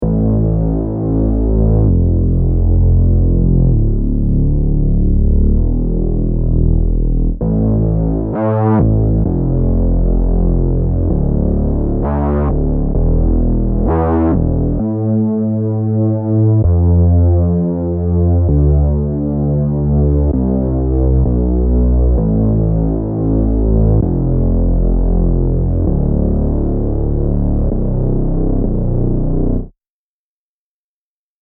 Reese Storm Bass by TXVXN is a cutting-edge VST delivering iconic reese bass sounds with rich textures, powerful modulation, and professional-grade quality.
Built with meticulous attention to detail, this VST provides thick bass textures, complex harmonics, and intense modulation to produce powerful, earth-shaking sounds.
reese-bass-solo.mp3